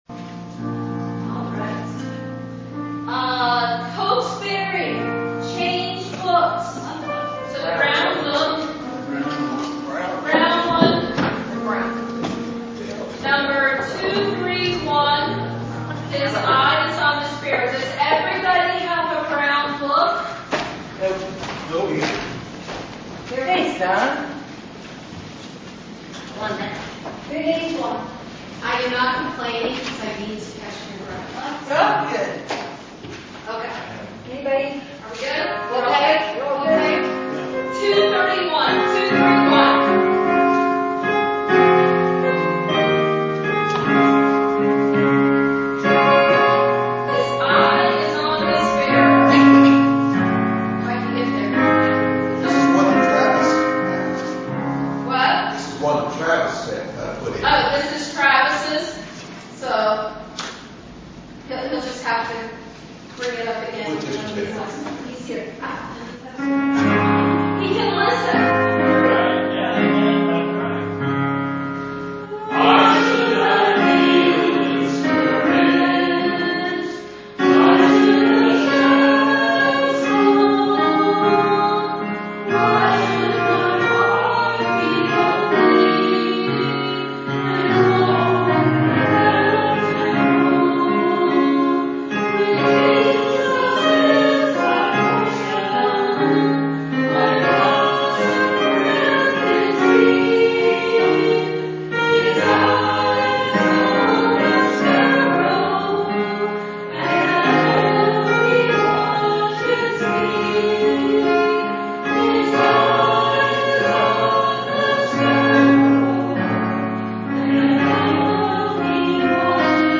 Hymn Sing
It has been said that each hymn contains a sermon/message, so consider taking some time to read all of the verses to reveal the full message of the hymn...Each recording includes statements made before the hymn is sung...as well as any comments made after the singing of the preceding hymn.